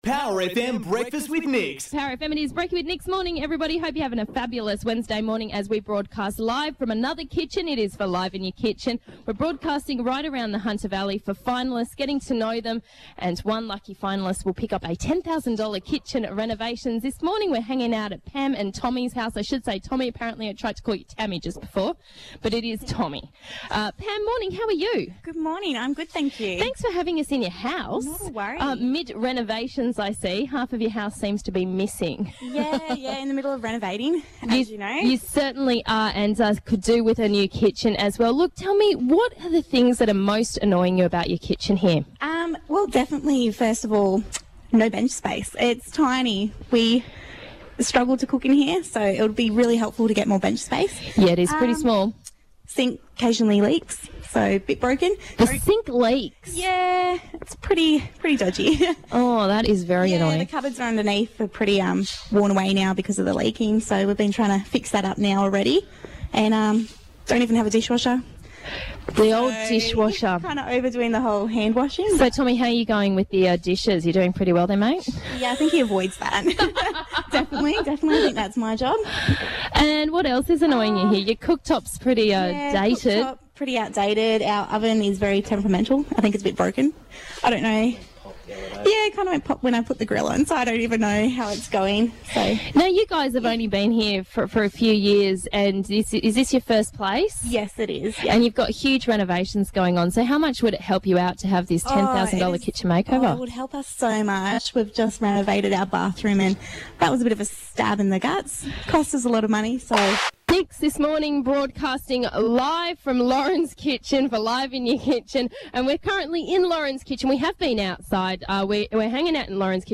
Over 3 months, once a week I broadcast live from someones kitchen…why?